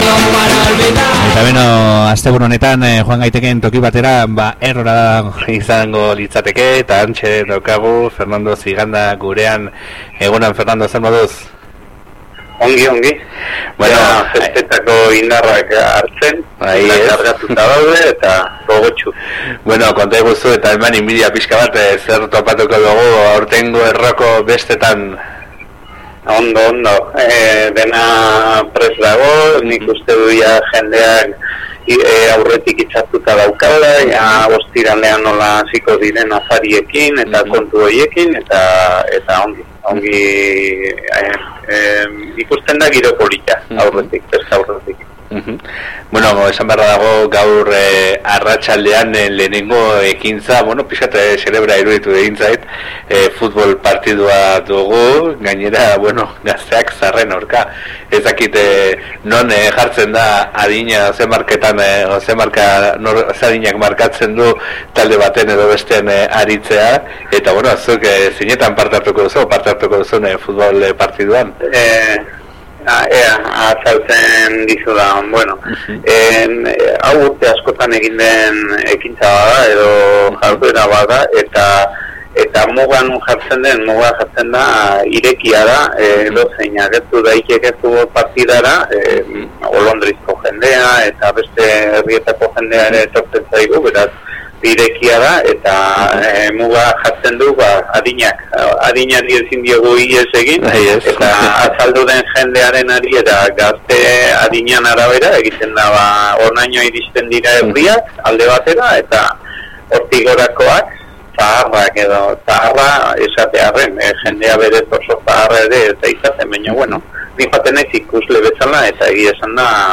Kartela: Klikatu hemen elkarrizketa jaisteko